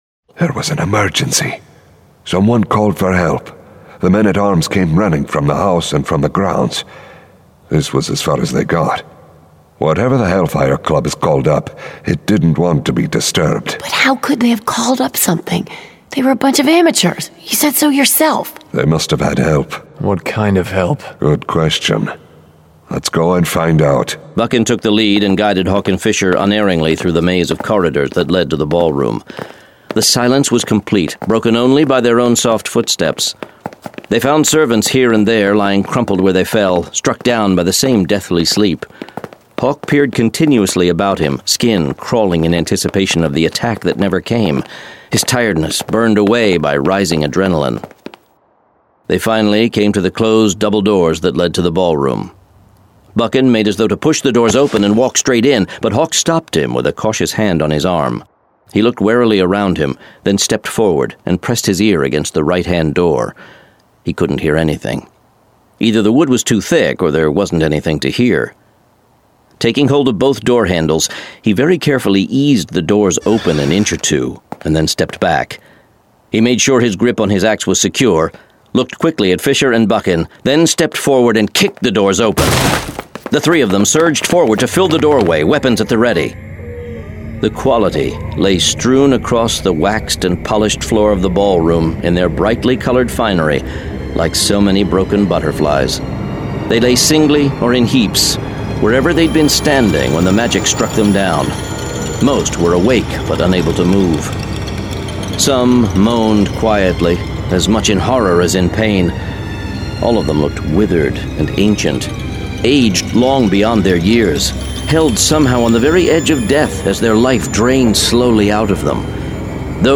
Forest Kingdom Saga: Hawk and Fisher 3: The God Killer [Dramatized Adaptation]